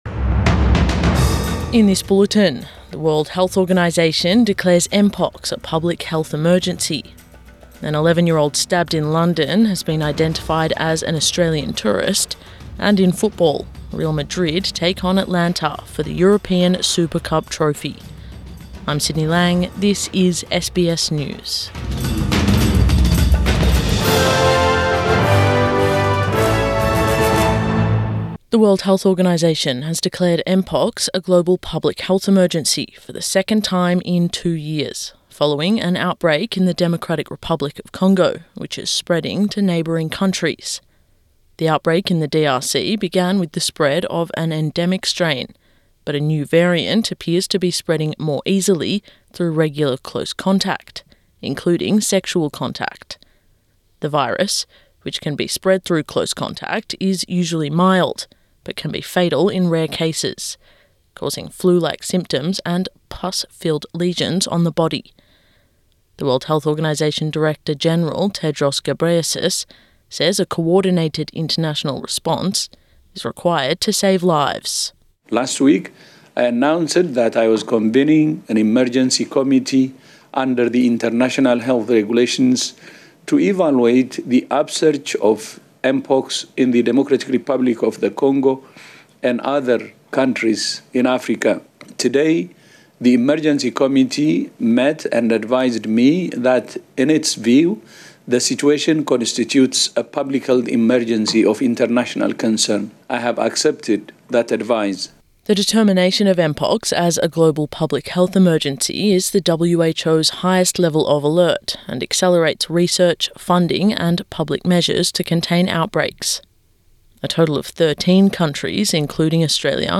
Morning News Bulletin 15 August 2024